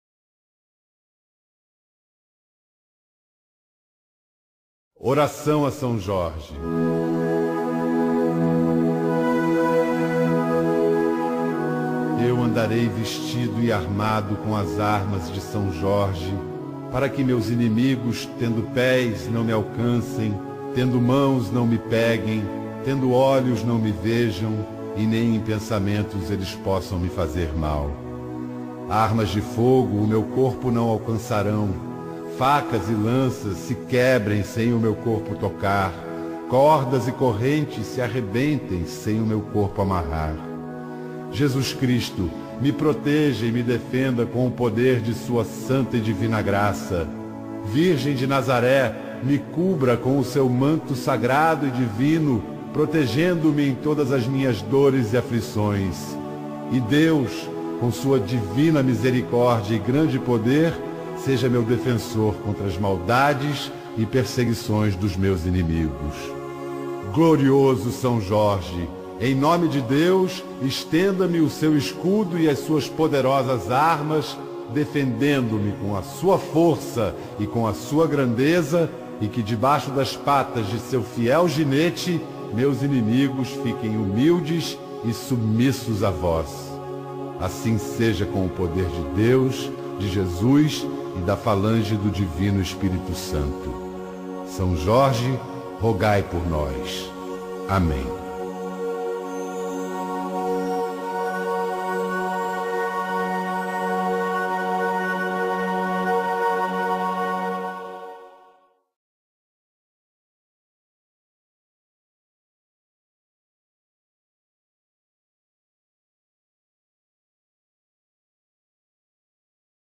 Voz: Pedro Bial
Oracao-a-Sao-Jorge-Narracao-Pedro-Bial.mp3